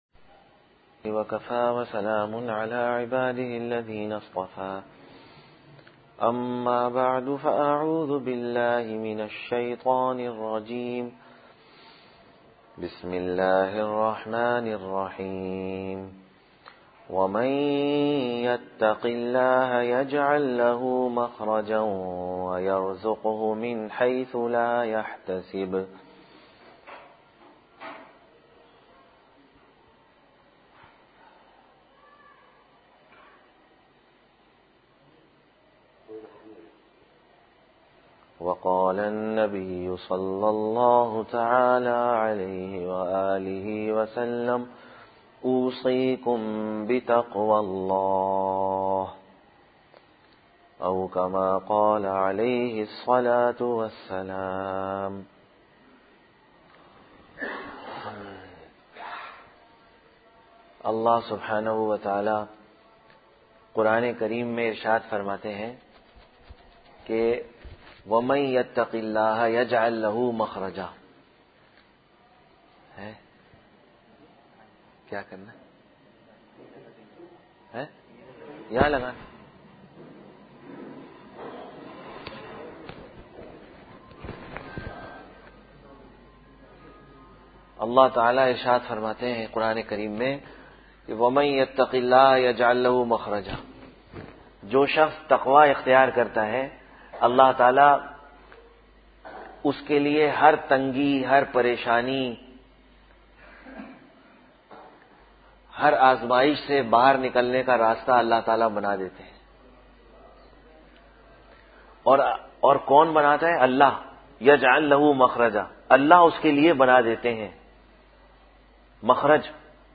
2-Jan-2015 Juma Bayan Jama Masjid Goth Ghulam Muhammad